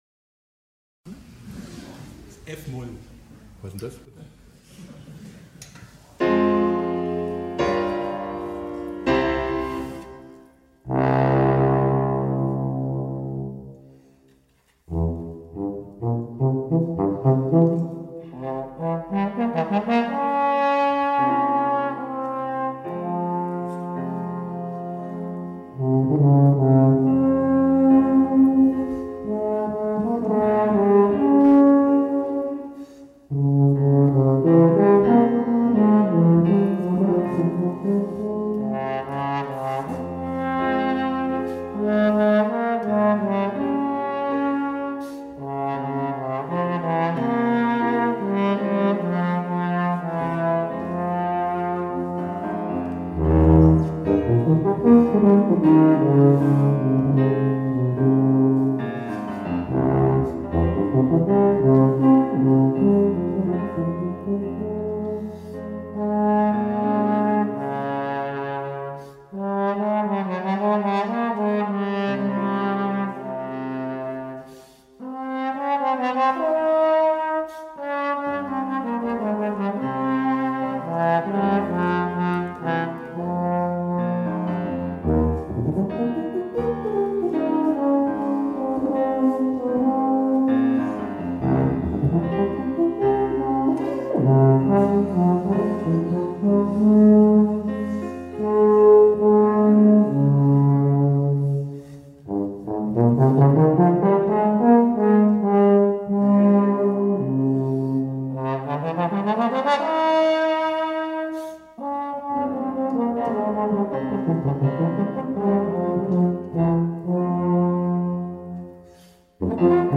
chardash-monti.trombon-und-tuba.mp3